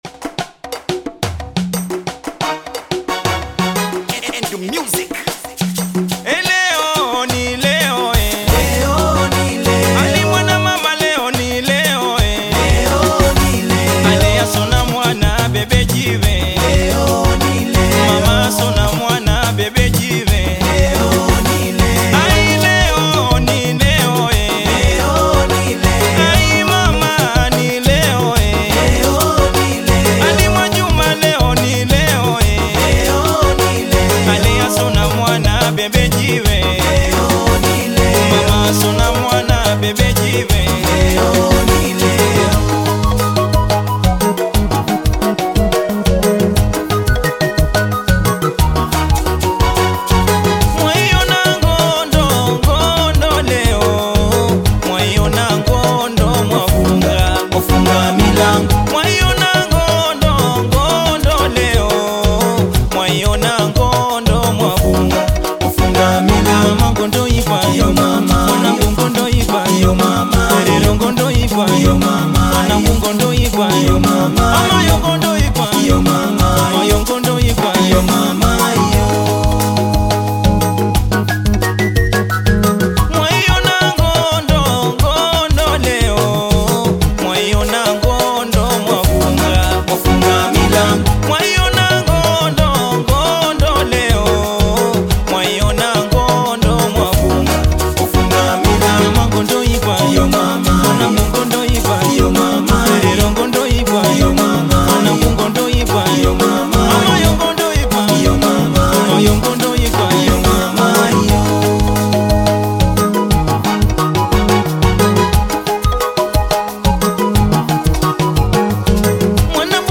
BAIKOKO TANGA